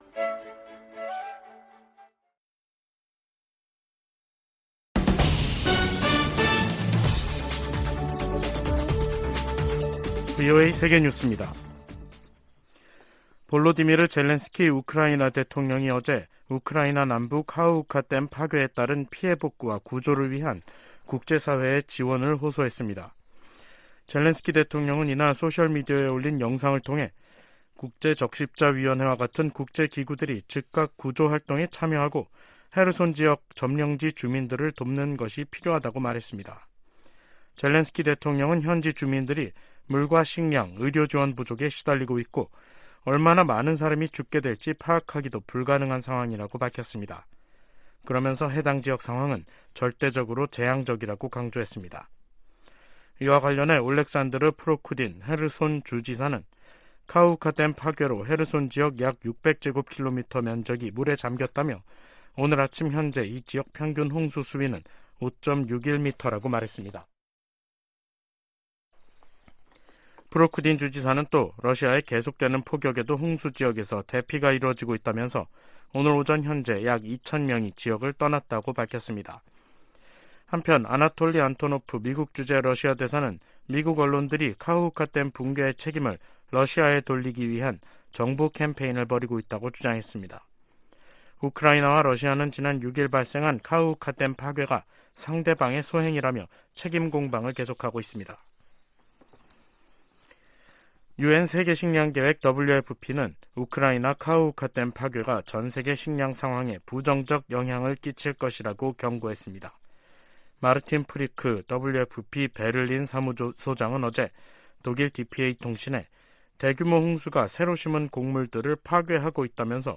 VOA 한국어 간판 뉴스 프로그램 '뉴스 투데이', 2023년 6월 8일 2부 방송입니다. 미국은 국제원자력기구 이사회에서 북한의 전례 없는 미사일 발사를 거론하며 도발적 행동에 결과가 따를 것이라고 경고했습니다. 한국의 윤석열 정부는 개정된 안보전략에서 '종전선언'을 빼고 '북 핵 최우선 위협'을 명시했습니다. 백악관의 커트 캠벨 인도태평양조정관은 북한 문제를 중국과의 주요 협력 대상 중 하나로 꼽았습니다.